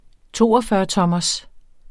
Udtale [ ˈtoʌfɶːʌˌtʌmʌs ]